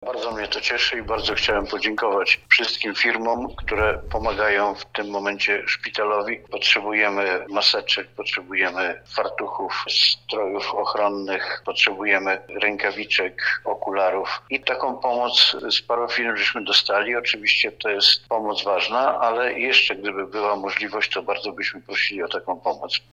To nieoceniona pomoc w tym trudnym czasie podkreśla starosta powiatu tarnobrzeskiego Jerzy Sudoł.